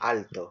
[ˈal̪t̪o] 'tall'
Es-alto.oga.mp3